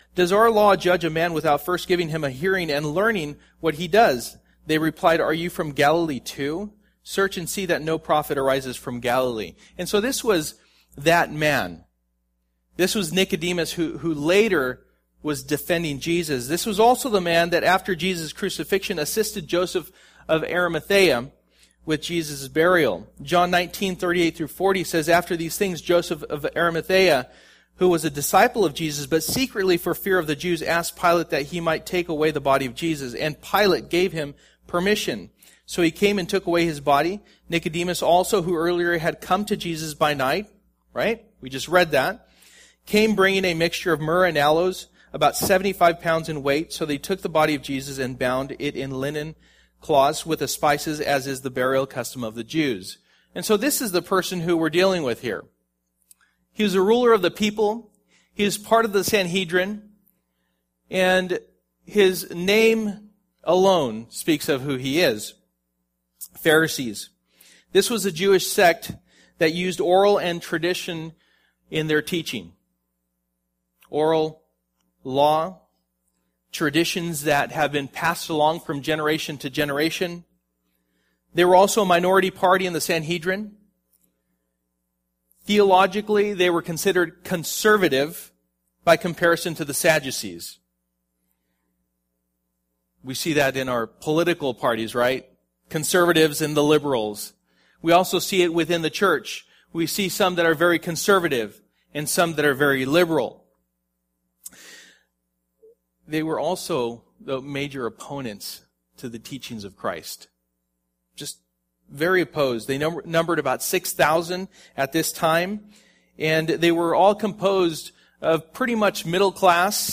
Believe Passage: John 3:1-15 Service: Sunday Morning %todo_render% « Does Jesus Believe Your Belief?